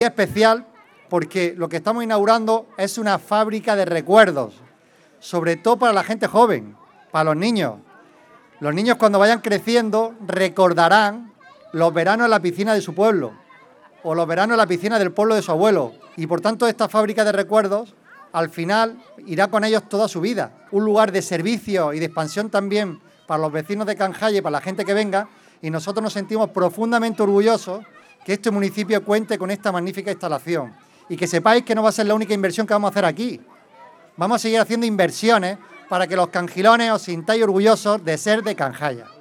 21-06_canjayar_presidente.mp3